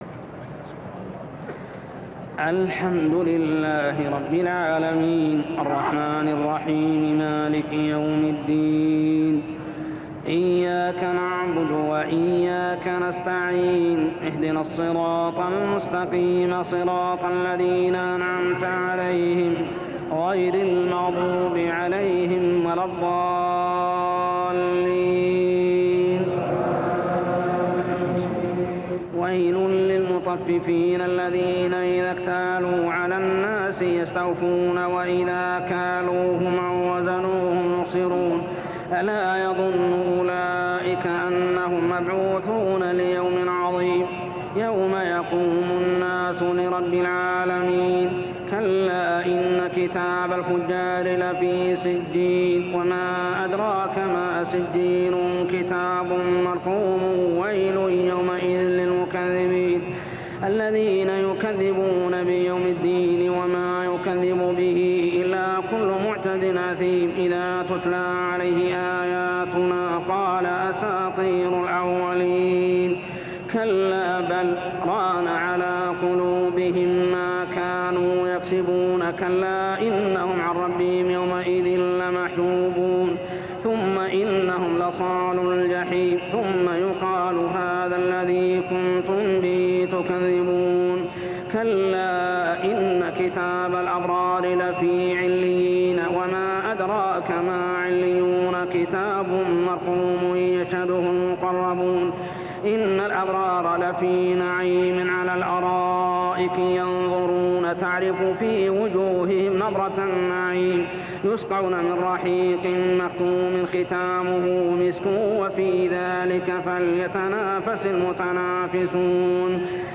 صلاة التراويح عام 1398هـ من سورة المطففين كاملة حتى سورة البلد كاملة | Tarawih prayer from Surah Al-muttaffifeen to surah Al-balad > تراويح الحرم المكي عام 1398 🕋 > التراويح - تلاوات الحرمين